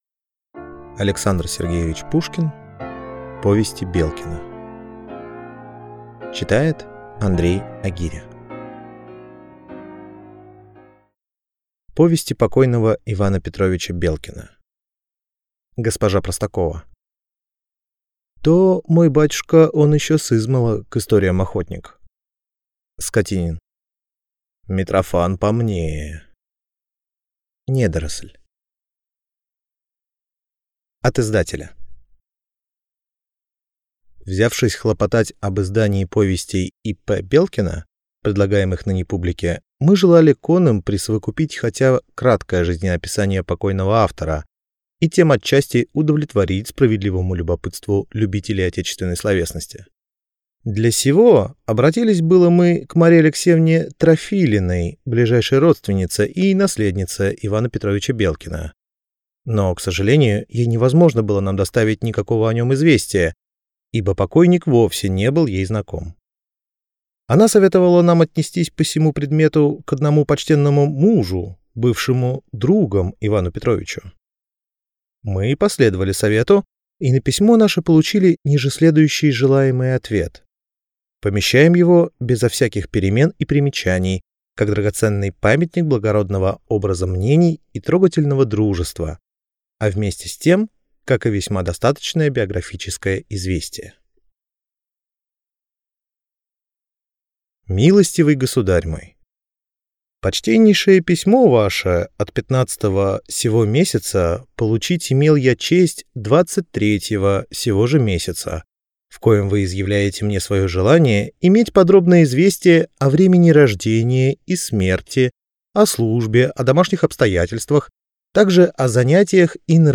Аудиокнига Повести Белкина | Библиотека аудиокниг